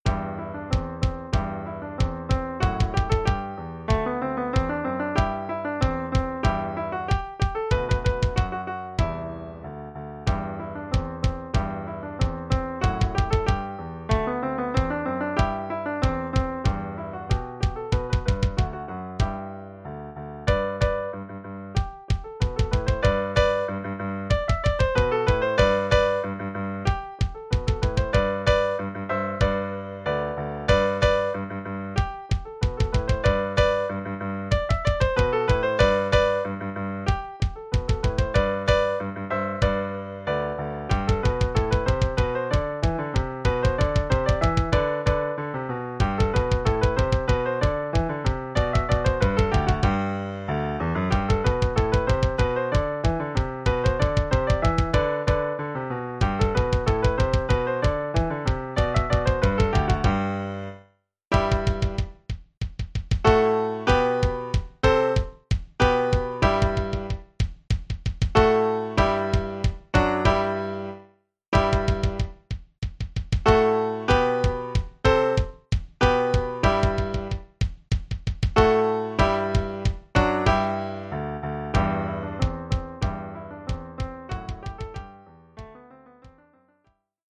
Oeuvre pour caisse claire et piano.